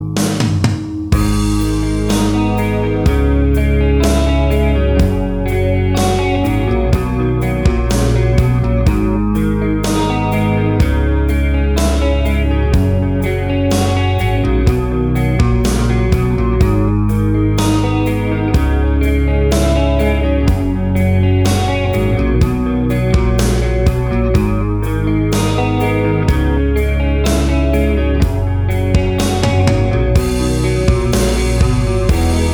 • Качество: 320, Stereo
гитара
спокойные
без слов
инструментальные
рок
Рингтон мирового хита без слов.